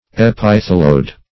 epitheloid - definition of epitheloid - synonyms, pronunciation, spelling from Free Dictionary Search Result for " epitheloid" : The Collaborative International Dictionary of English v.0.48: Epitheloid \Ep`i*the"loid\, a. (Anat.)